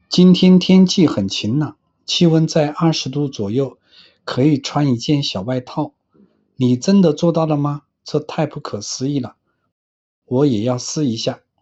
Voz autêntica para vlogs de vida diária
Dê vida às suas histórias pessoais com uma voz de IA calorosa e conversacional projetada para vlogs diários, conteúdo de estilo de vida e narrativas íntimas.
Texto para Fala
Tom Conversacional
Cadência Natural